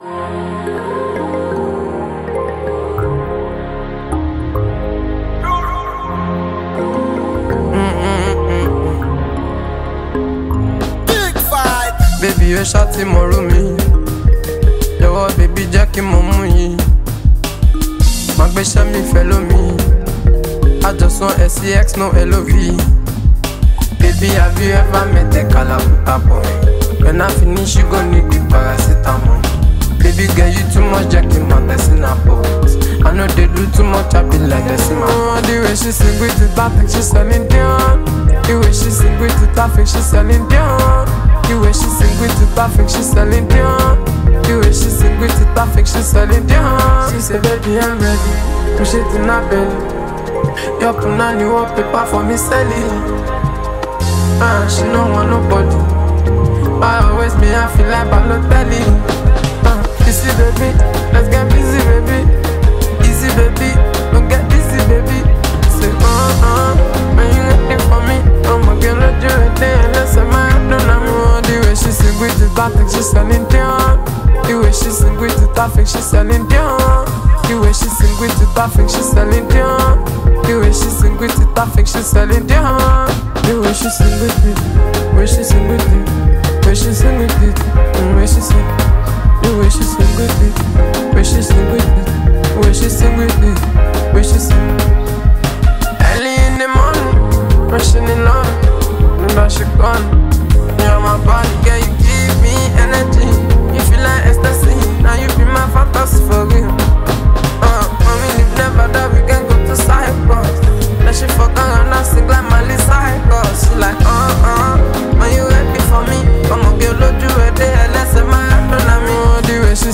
Known for his infectious melodies and unique Afrobeat sound